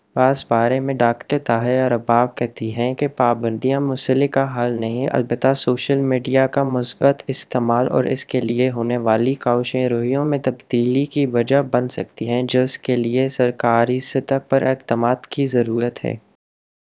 deepfake_detection_dataset_urdu / Spoofed_TTS /Speaker_03 /102.wav